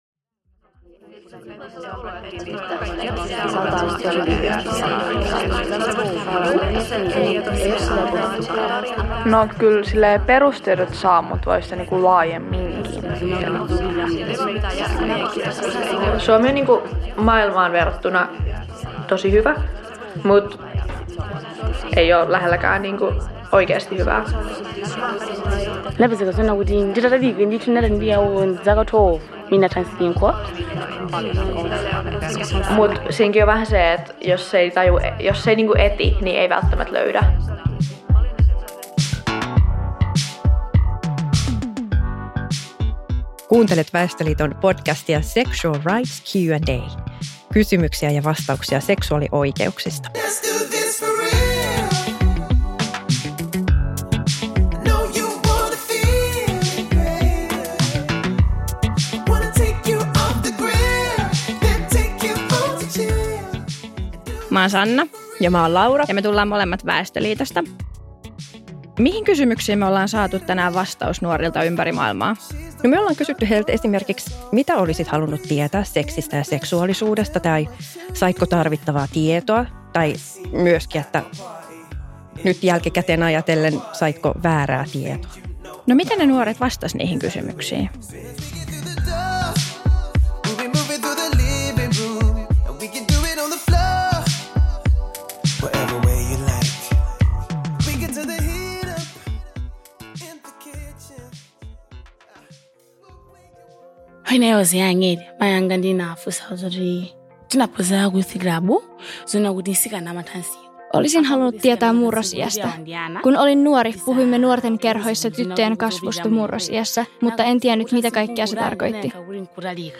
Keskustelemassa suomalaisia nuoria ja malawilainen nuori